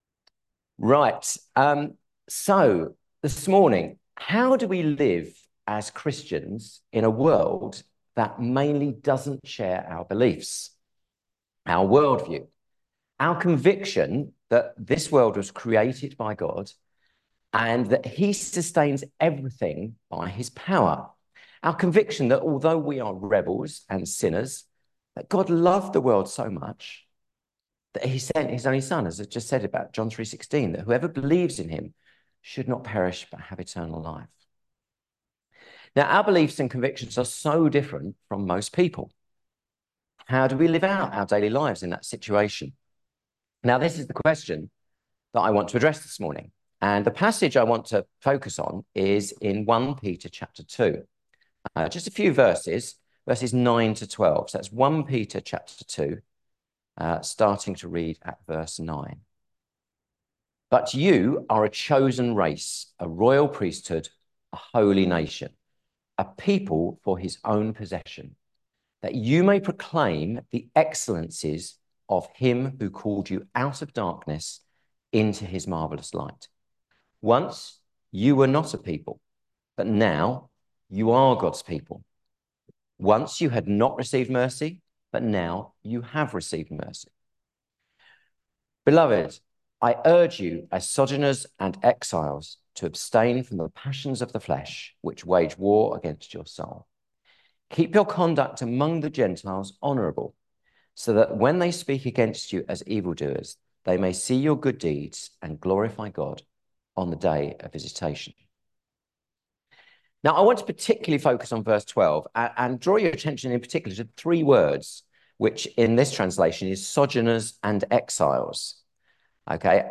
1 Peter 2:9-12 Service Type: Sunday Service Topics: Daily Life , Living , Sanctification , Worldliness « Reaching People for Christ